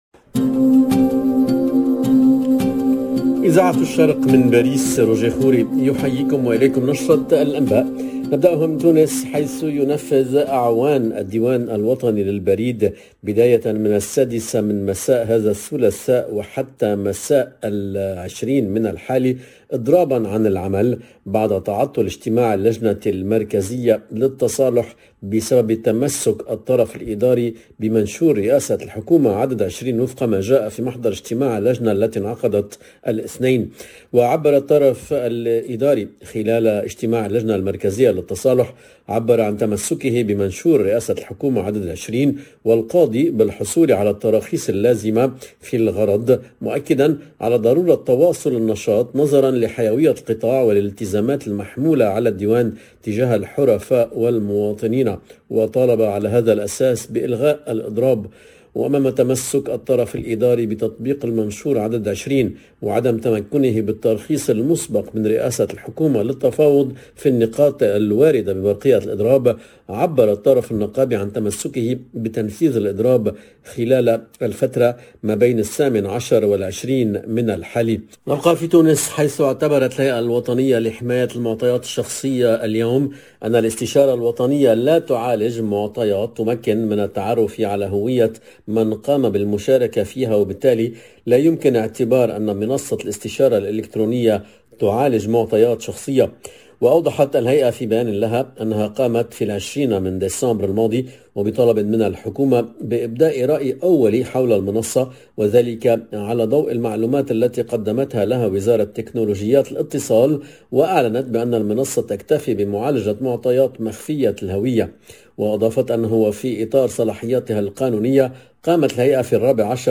LE JOURNAL DE MIDI 30 EN LANGUE ARABE DU 18/01/22